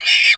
CapersProject/CREATURE_Squeel_03_mono.wav at d0a8d3fa7feee342666ec94fc4a0569fb8c8c2c9
CREATURE_Squeel_03_mono.wav